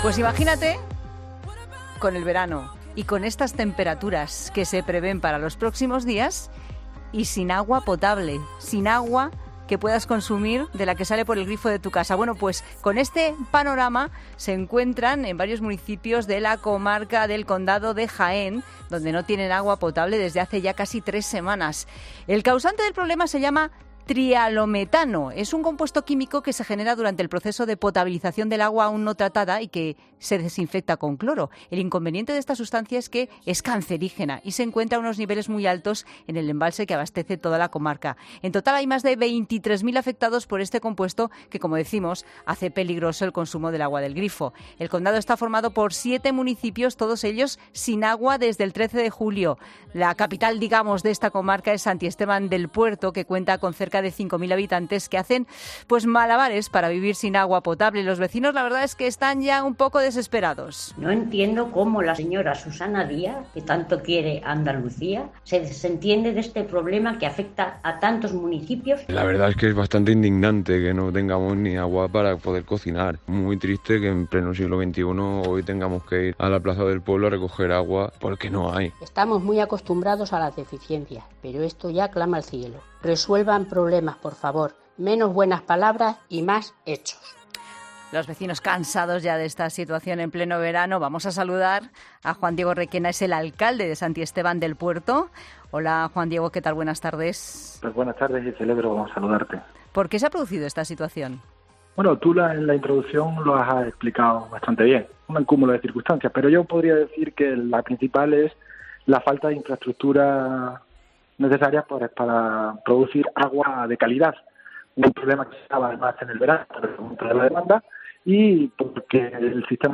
Los vecinos de la comarca del Condado, que agrupa siete municipios, llevan desde el 13 de julio dependiendo de los camiones cisterna que llegan con agua potable. para comentar su situación ha participado en 'Mediodía COPE' Juan Diego Requena, alcalde de Santisteban del Puerto, el municipio principal de la comarca.